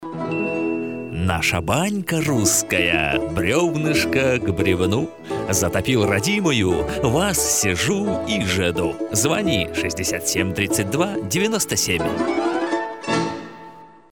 Friendly, plastic and artistic voice.
Sprechprobe: Sonstiges (Muttersprache):